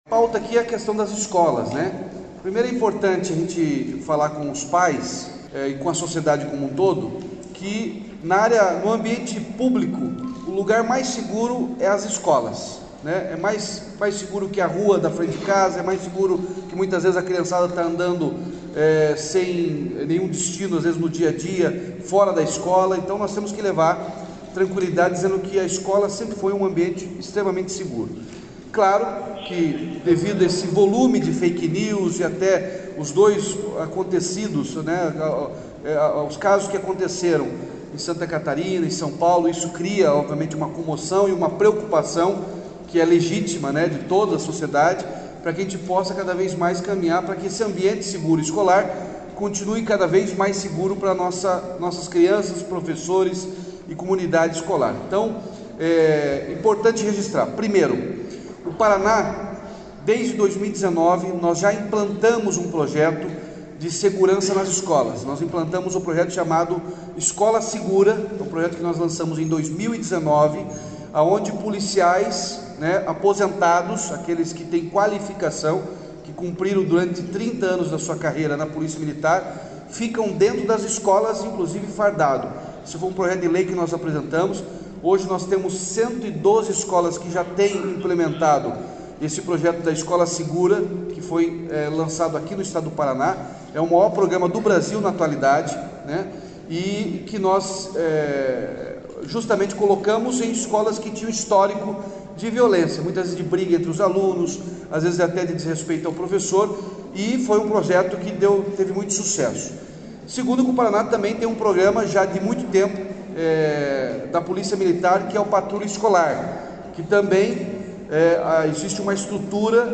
Sonora do governador Ratinho Junior sobre o anúncio de medidas para garantir a segurança nas escolas estaduais